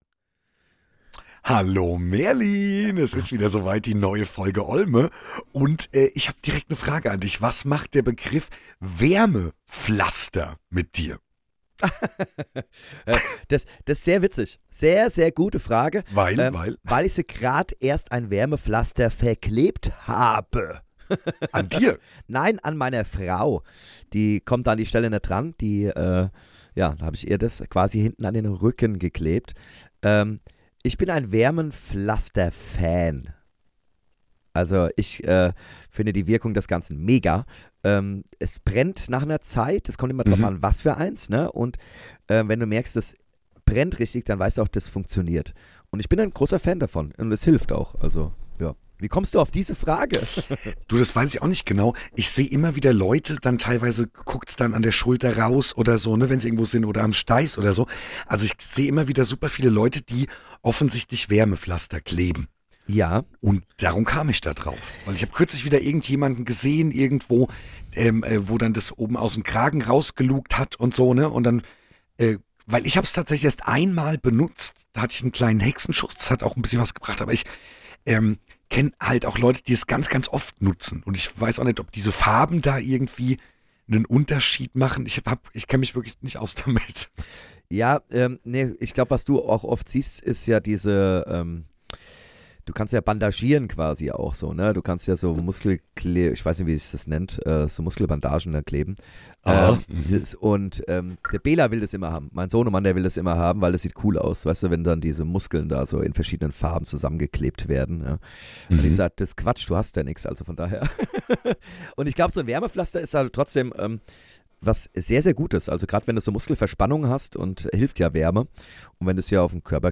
Wie immer ungeschnitten, ungefiltert und roh - Achtung die Shownotes wurden mittels KI geschrieben :-) Mehr